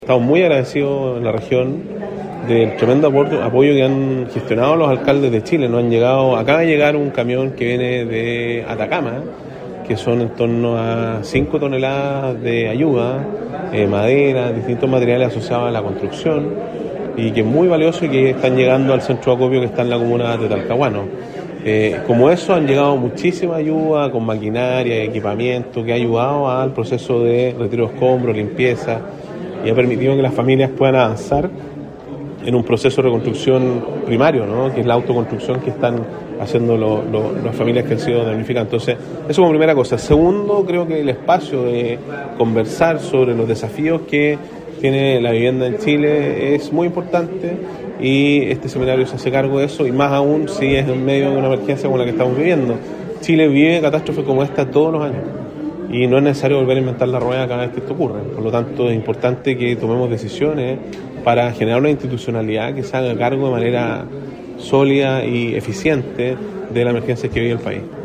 En tiempos de resiliencia y reconstrucción: Desafíos del desarrollo urbano y rural para el Chile del 2050 es el título del seminario organizado por la Asociación Chilena de Municipalidades y que se desarrollará en la Universidad del Bío-Bío hasta este viernes.